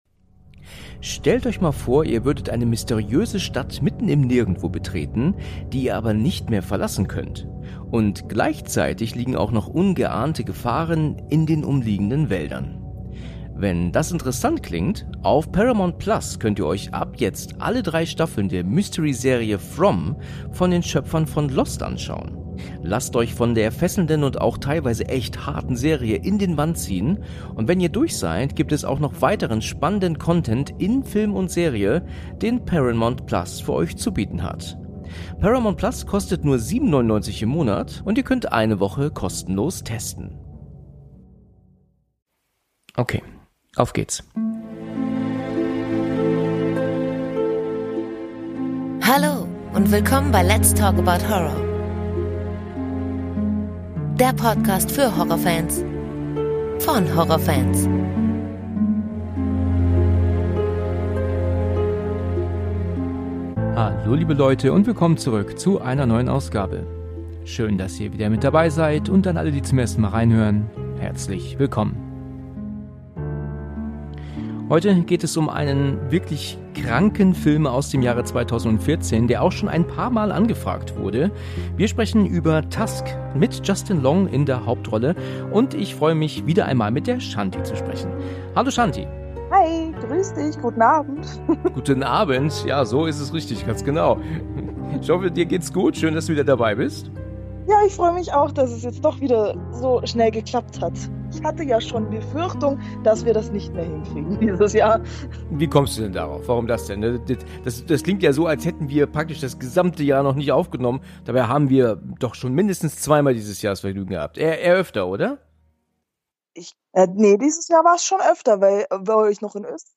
In diesem Podcast geht es um das coolste Genre überhaupt: Horror und Psychothriller! In jeder Folge bespreche ich mit wechselnden Gesprächspartnern einen guten (oder auch mal weniger guten) Film.